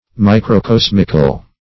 Microcosmic \Mi`cro*cos"mic\, Microcosmical \Mi`cro*cos"mic*al\,